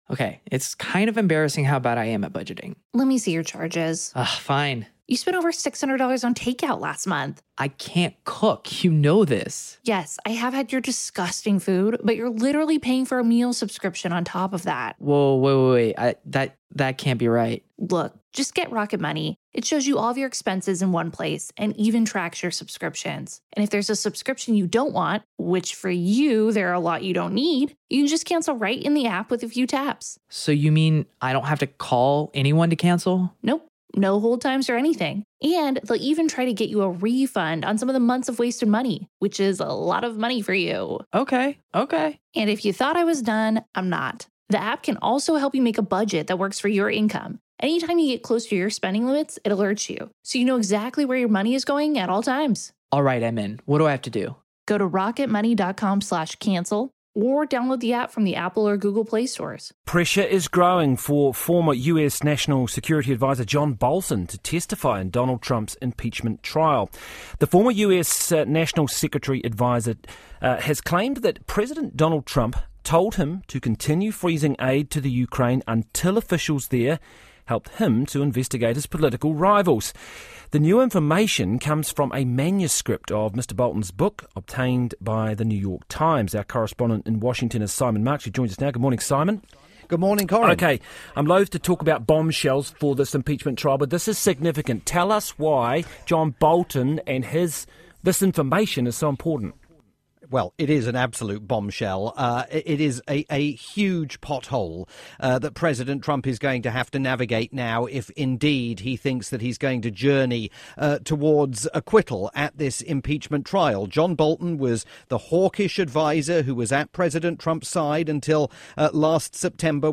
live report from Washington for Radio New Zealand's "Morning Report".